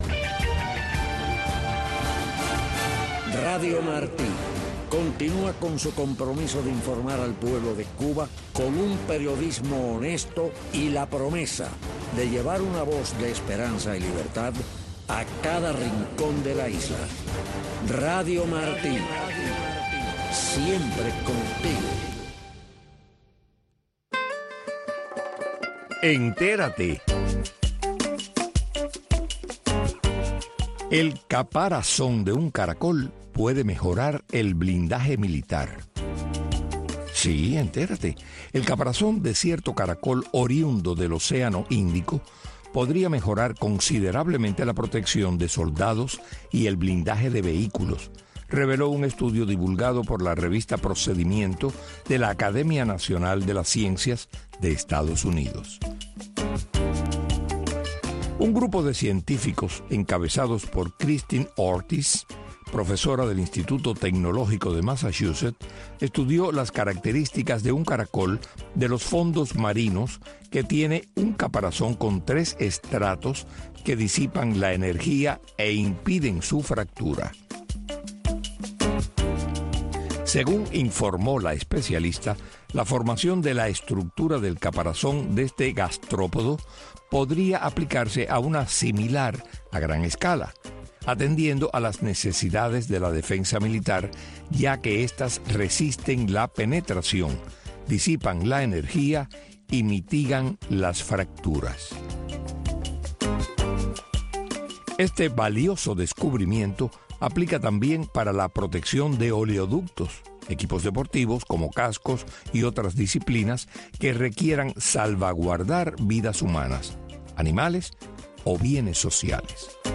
Programa humanista, preservador de buenas costumbres, reflexivo, aderezado con música y entrevistas. Las artes, el deporte, la ciencia, la política, e infinidad de tópicos, caben en este programa que está diseñado para enaltecer nuestras raíces, y para rendir tributo a esa bendita palabra que es Familia.